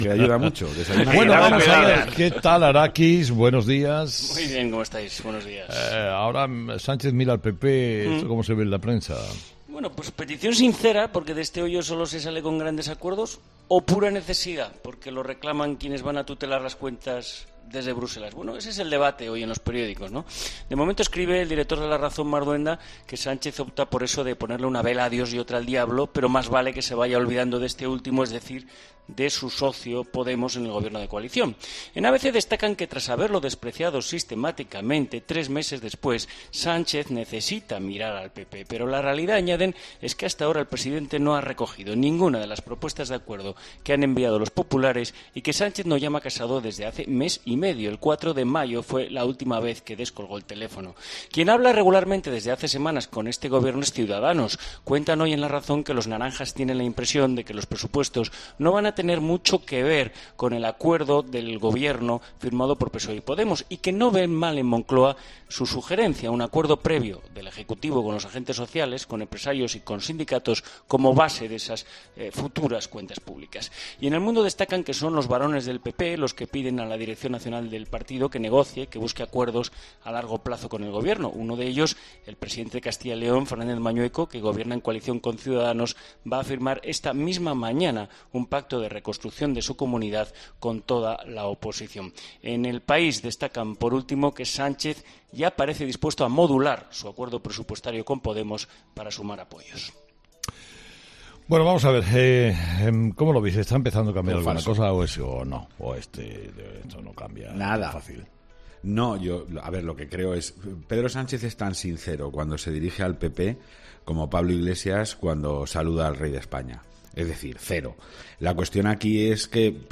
Un asunto que este miércoles han analizado en la tertulia de 'Herrera en COPE'.